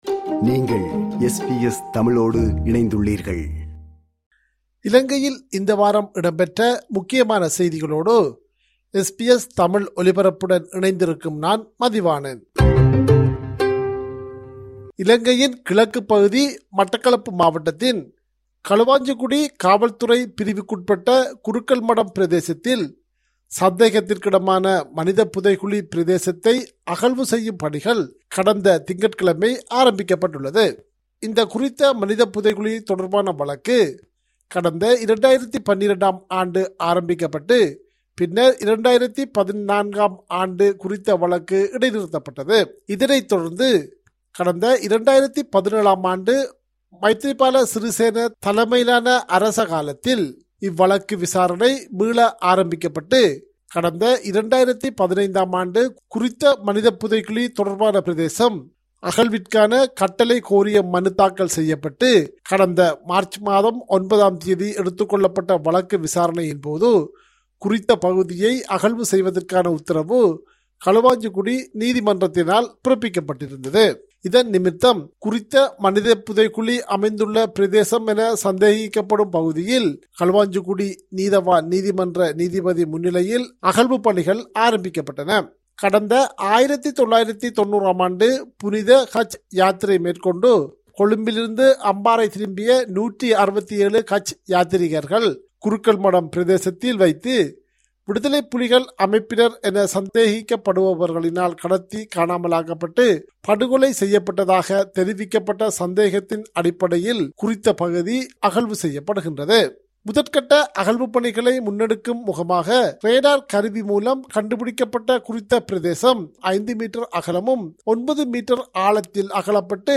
Top news from Sri Lanka this week